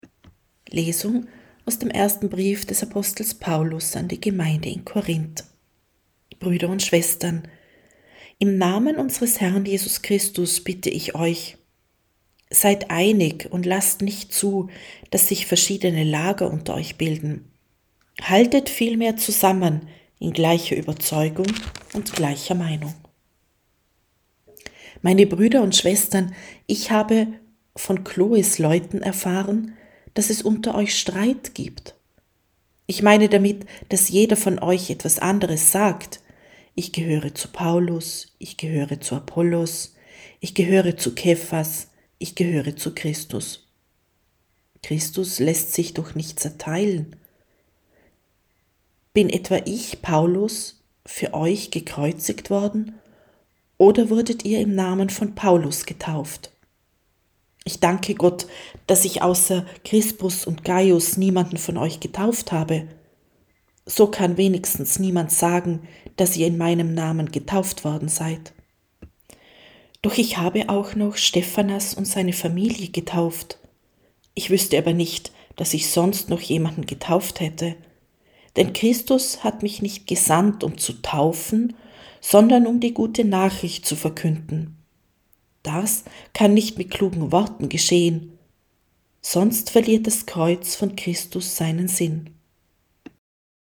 Wenn Sie den Text der 2. Lesung aus dem ersten Brief des Apostels Paulus an die Gemeinde in Korínth anhören möchten:
Wir wollen einen Versuch starten und werden ab dem Beginn des neuen Lesejahres die Texte in der Länge der biblischen Verfasser lesen.